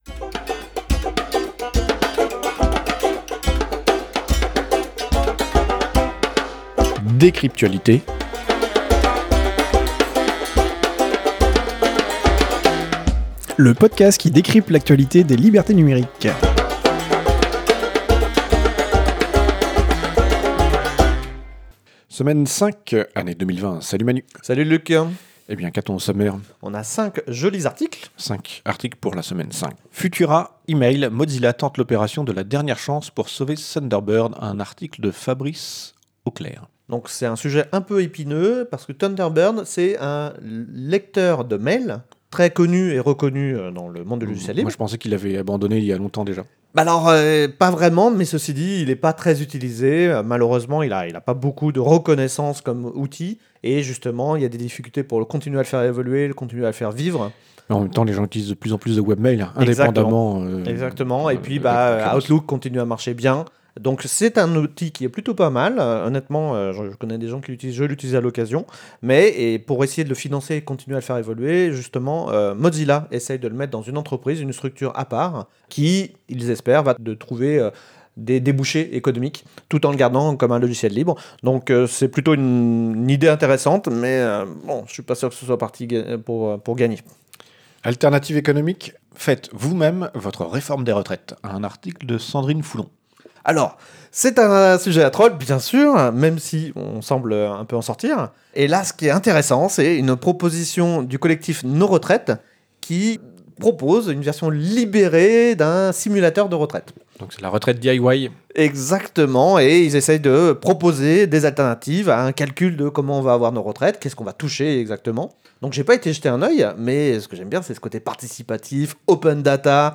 Studio d'enregistrement